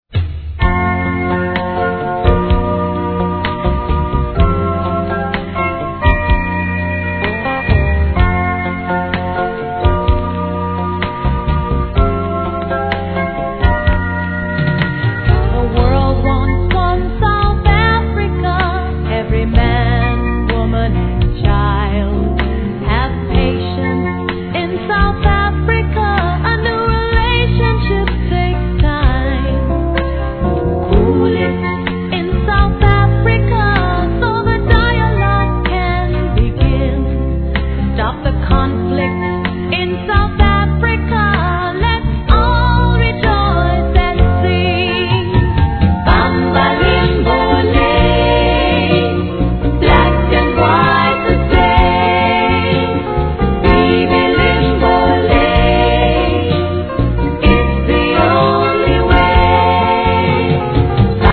REGGAE
GOODヴォーカル!